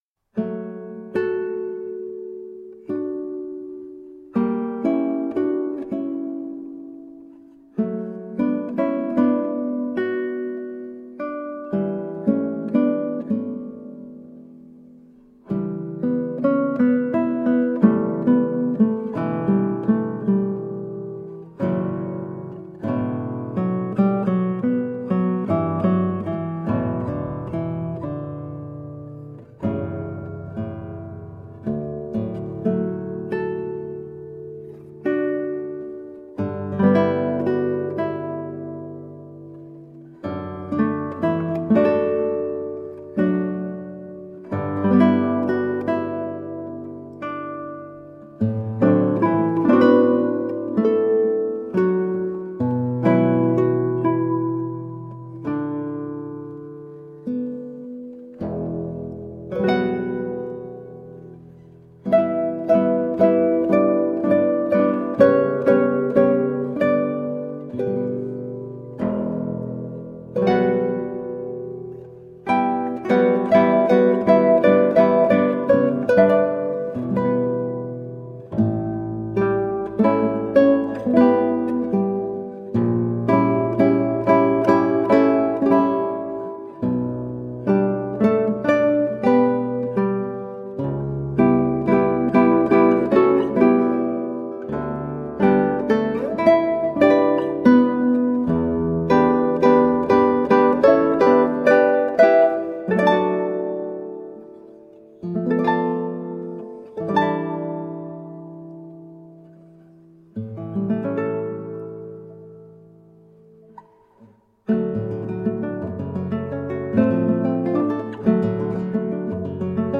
Colorful classical guitar.
Classical, Impressionism, Instrumental
Classical Guitar, Ukulele